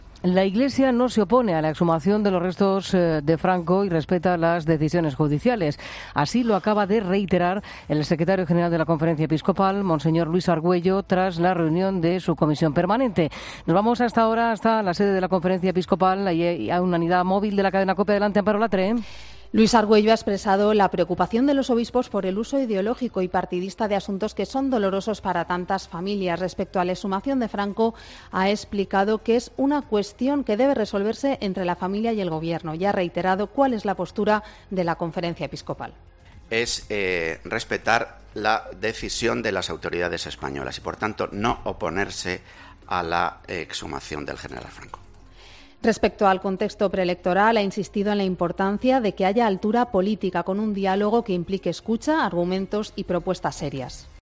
El secretario general de la CEE, Mons. Luis Argüello ha comparecido en rueda de prensa tra la reunión de la Comisión Permanente de otoño